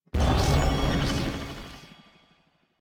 Minecraft Version Minecraft Version 1.21.5 Latest Release | Latest Snapshot 1.21.5 / assets / minecraft / sounds / mob / illusion_illager / prepare_mirror.ogg Compare With Compare With Latest Release | Latest Snapshot